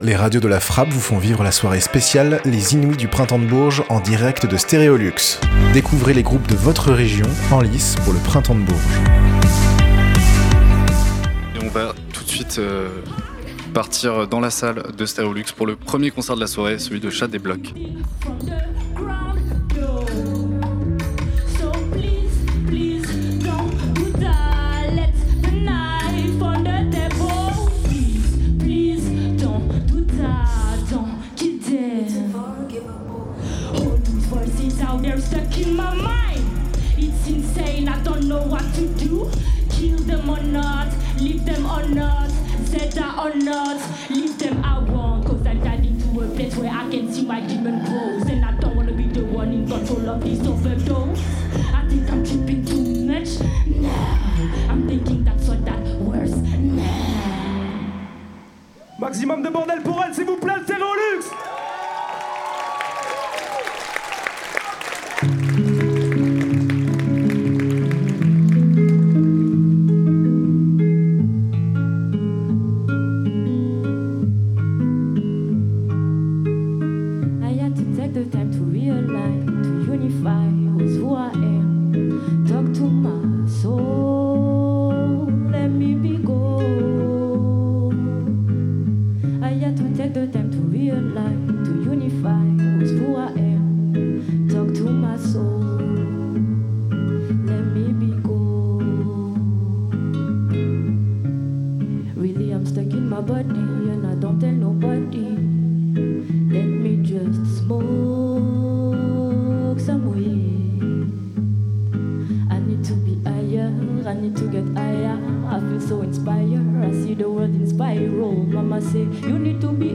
#2 : Live et Interview de